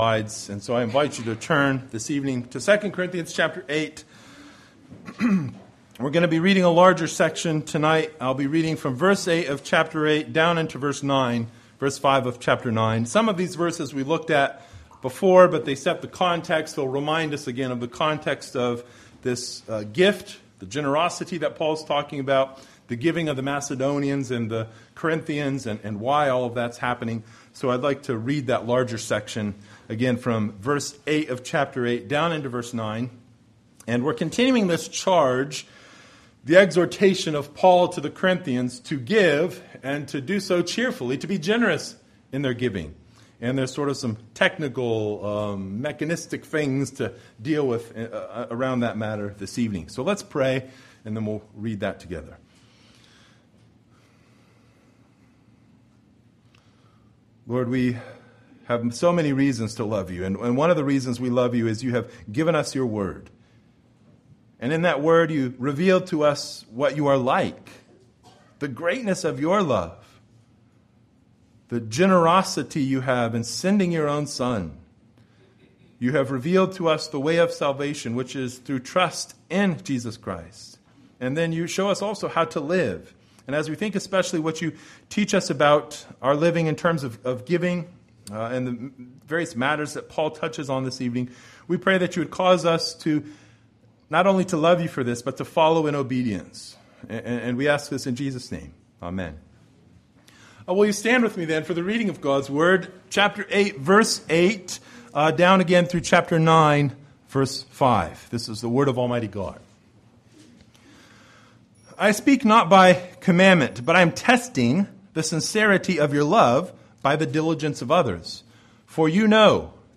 Passage: II Corinthians 8:8 - 9:5 Service Type: Sunday Evening